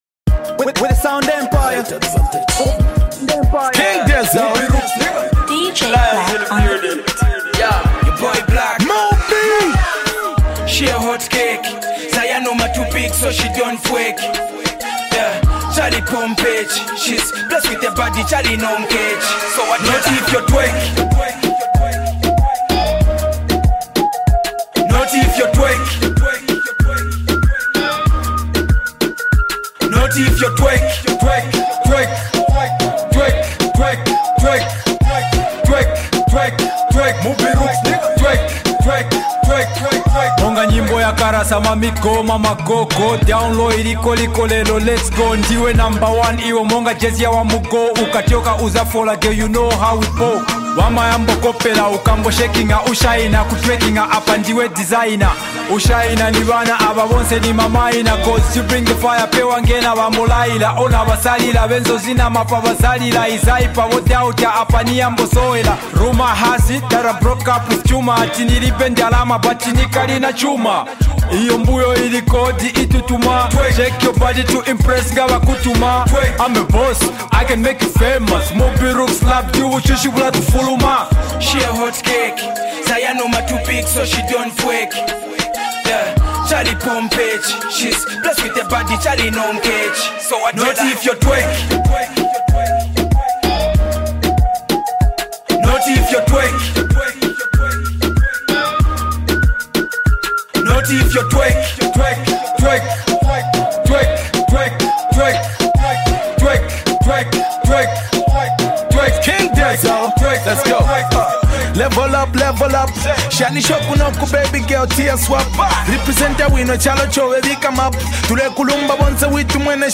dancehall track